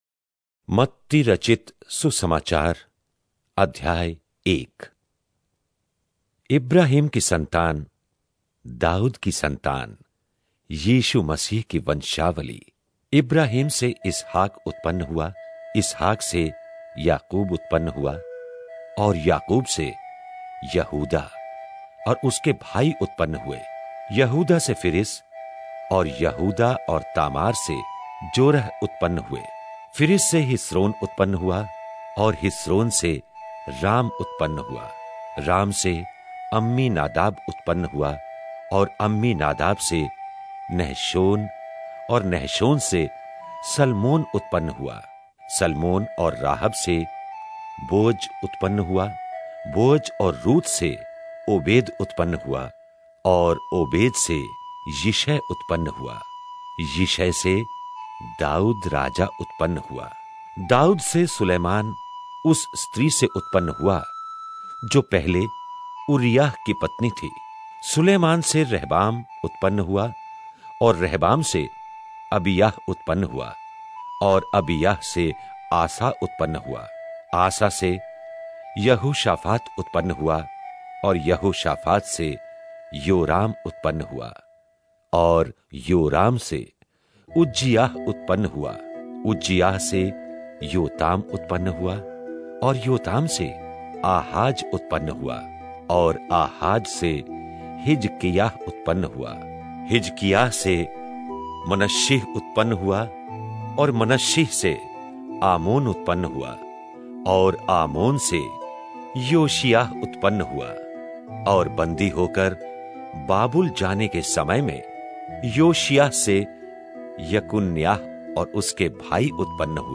Hindi Drama Audio Bible New Testament